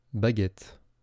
A baguette (/bæˈɡɛt/; French: [baɡɛt]
Fr-baguette.wav.mp3